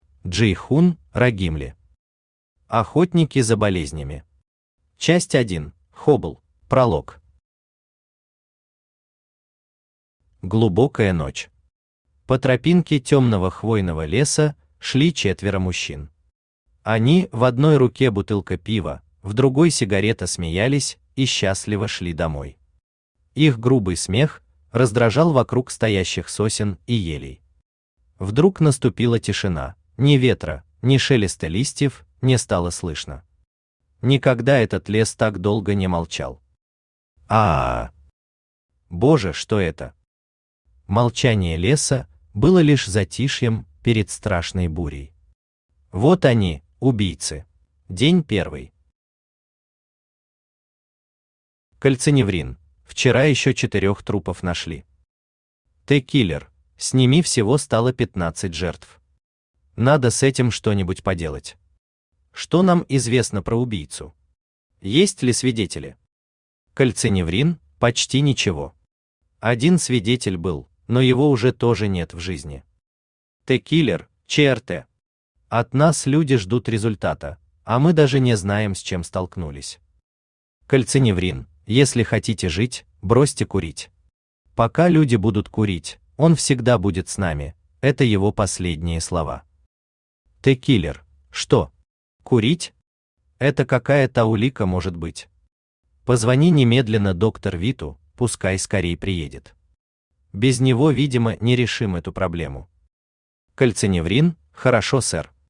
Аудиокнига Охотники за болезнями. Часть 1: ХОБЛ | Библиотека аудиокниг
Aудиокнига Охотники за болезнями. Часть 1: ХОБЛ Автор Джейхун Рагимли Читает аудиокнигу Авточтец ЛитРес.